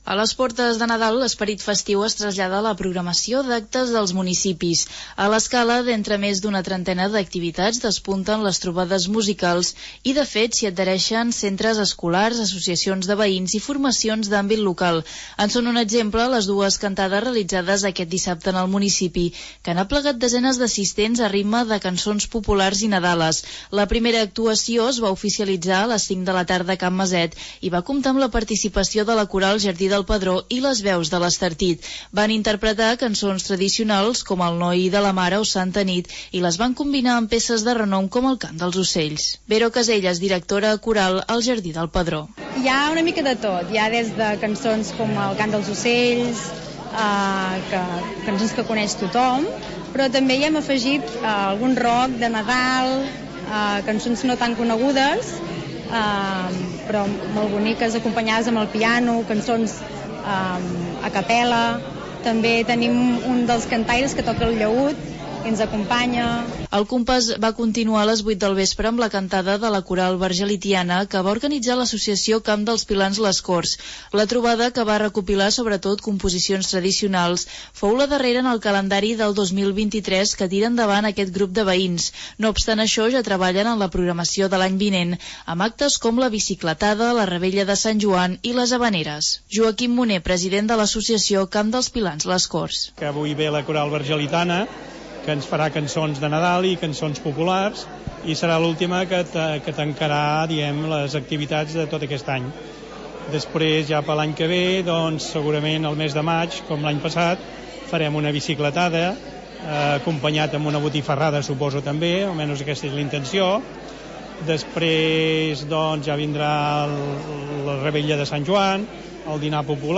Les corals 'Vergelitana', 'Jardí del Pedró' i 'Les veus de l'Estartit' han actuat aquest cap de setmana a l'Escala en el marc de les festes de Nadal. Les trobades musicals, que recopilen cançons populars i nadales, estan consagrades en el programa d'actes de desembre del municipi i atrauen desenes d'assistents.